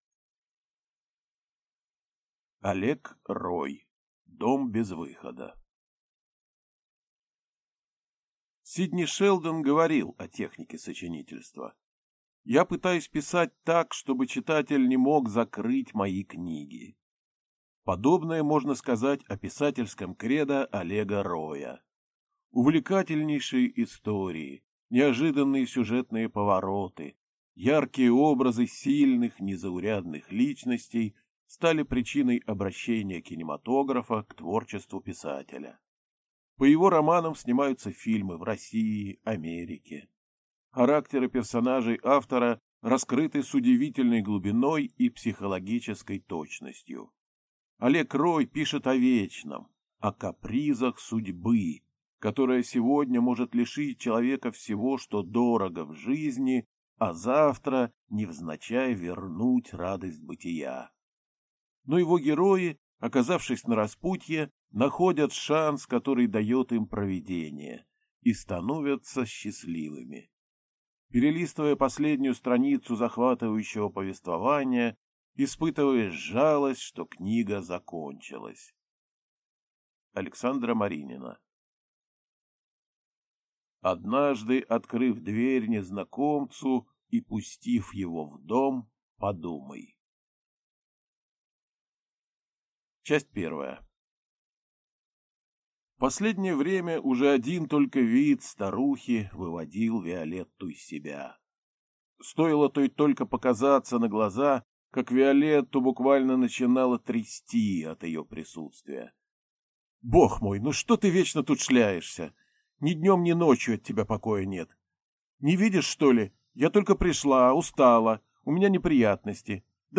Аудиокнига Дом без выхода | Библиотека аудиокниг